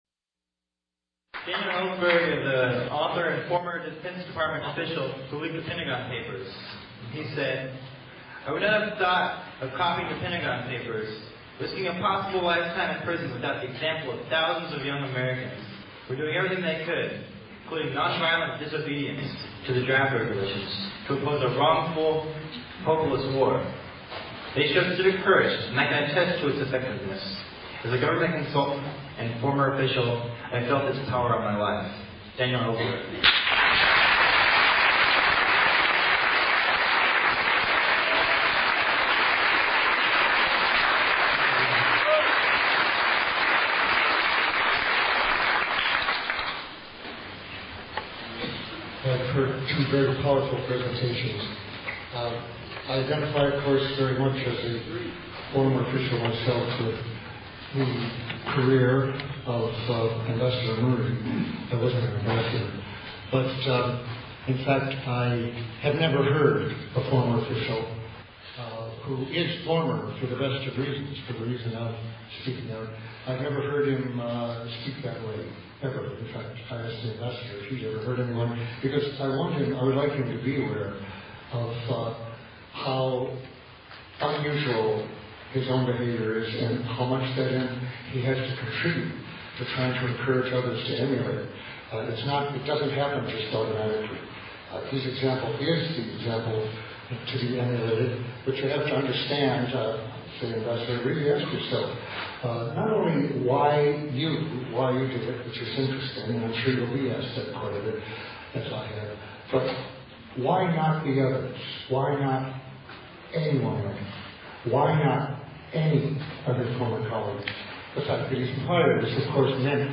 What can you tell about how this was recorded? The Bush Crimes Commission Campus Tour returned to UC Berkeley to kick off the West Coast leg of the tour on May 3rd. A crowd of 350- 400 people attended the Bush Crimes Commission event that brought together a powerful group of whistle blowers, government defectors and speakers who all spoke to